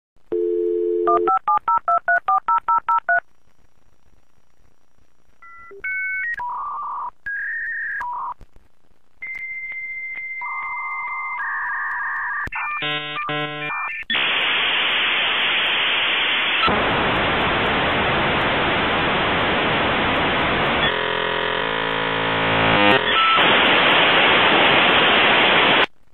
Dial-Up-Internet-Sound-Old-School.mp3